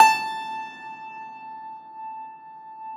53e-pno17-A3.wav